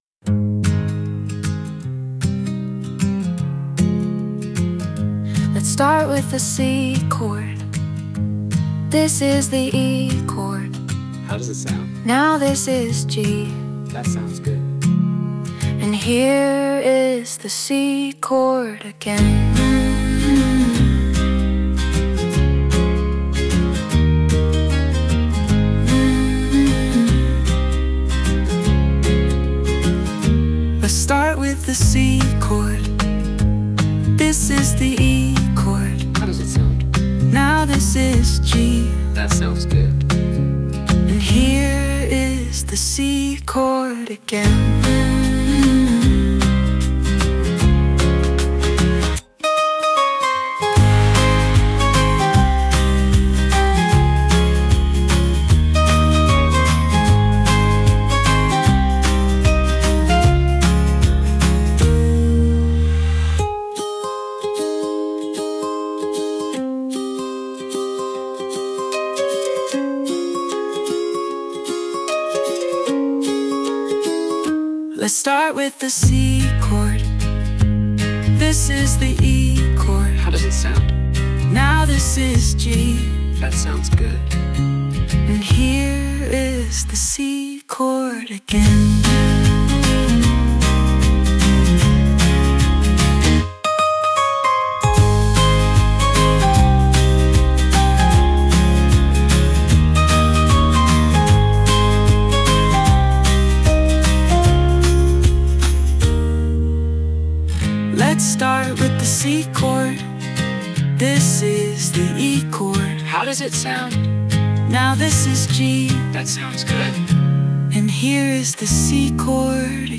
Ich habe mal eine Chord Progression in Suno versucht. Suno spielt unterschiedliche Akkorde, nur nicht die, die ich haben will.
Prompts: catchy, singer-songwriter, female vocals, minimal Anhänge Chord progression.mp3 Chord progression.mp3 2,8 MB